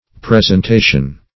Presentation \Pres`en*ta"tion\, n. [L. praesentatio a showing,